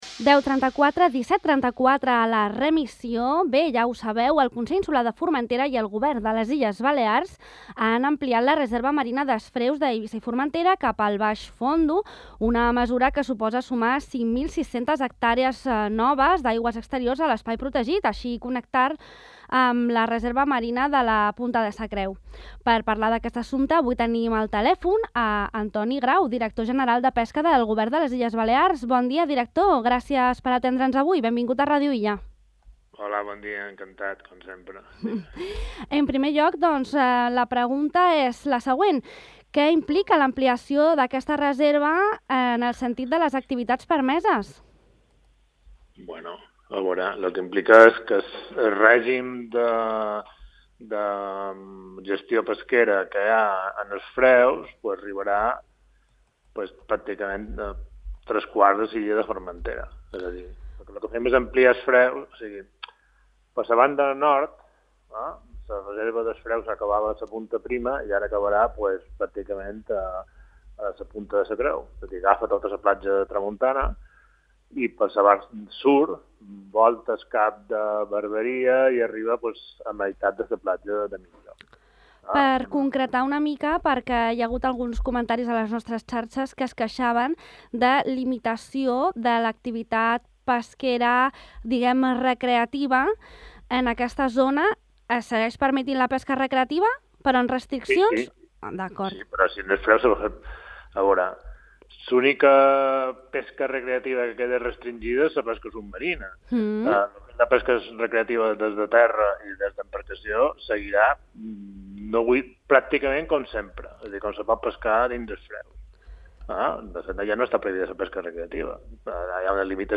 Al 'De Far a Far' de Ràdio Illa hem entrevistat el director general de Pesca del govern de les Illes Balears, Antoni M. Grau.